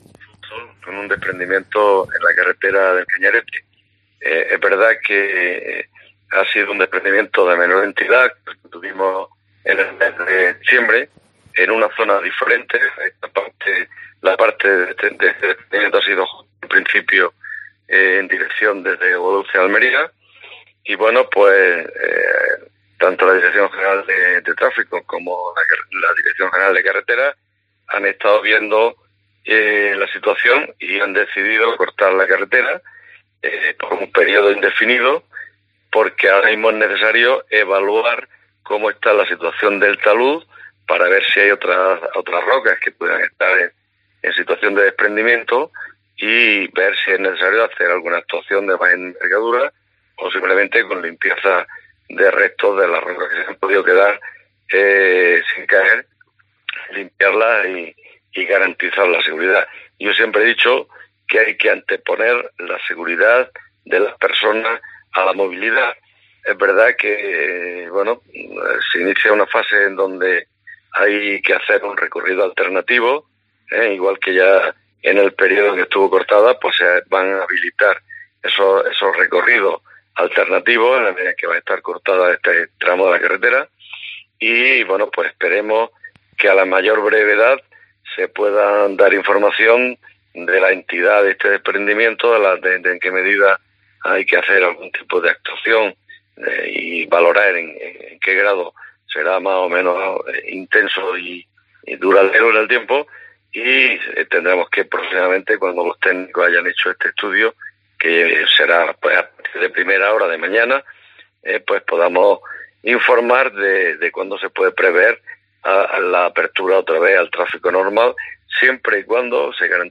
Subdelegado del Gobierno en Almería, Manuel de la Fuente
Manuel de la Fuente, subdelegado en Almería, sobre el cierre de El Cañarete por desprendimientos.